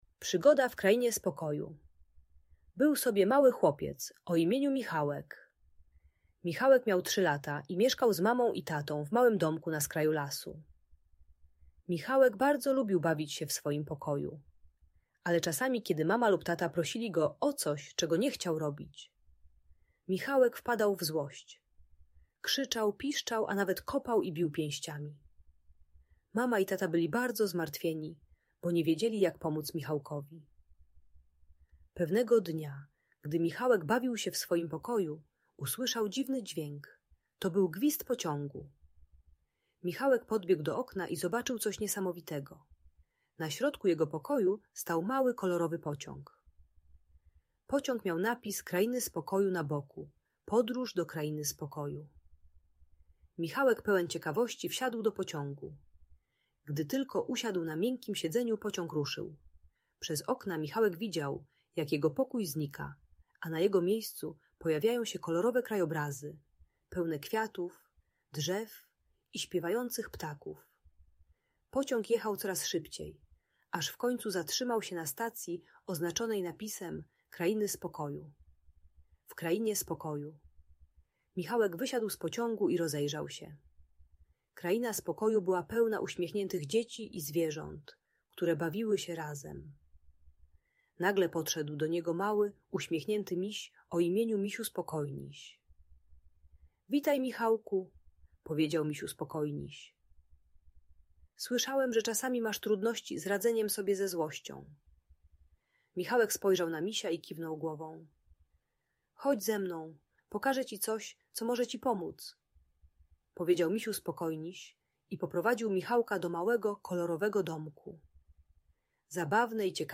Przygoda Michałka w Krainie Spokoju - Audiobajka